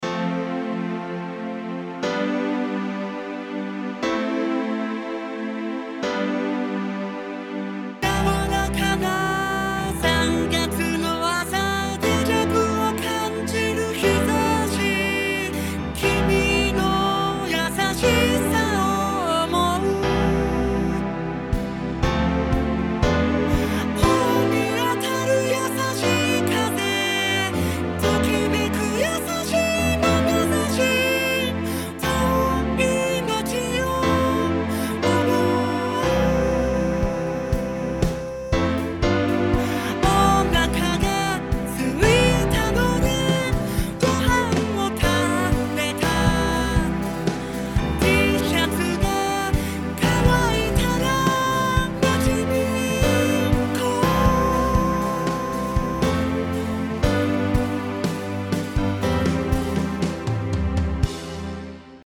というわけで、ボカロを使い、初のDTMでの曲づくりをやってみた。
１コーラスだけど、とりあえず最初にしては良い感じにまとまったかな？
ボーカロイド Ken が歌う、「おなかがすいたので、ごはんを食べた」 :-)